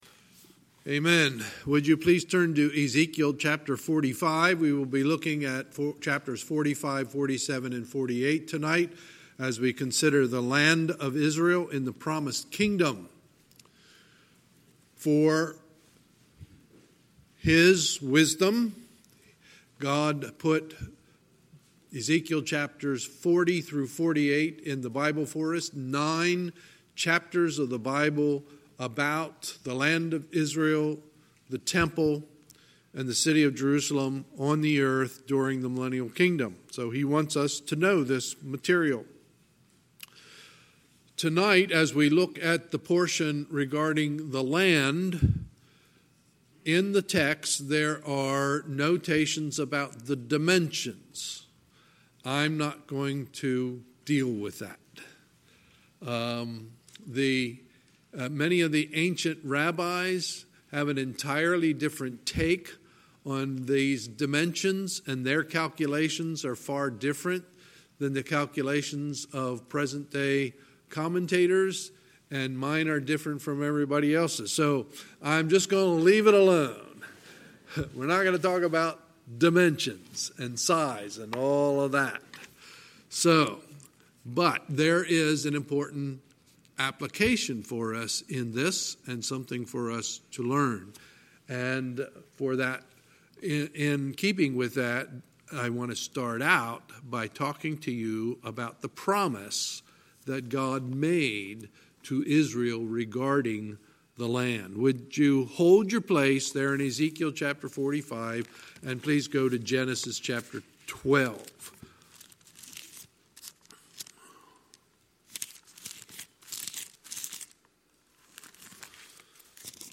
Sunday, September 15, 2019 – Sunday Evening Service
Sermons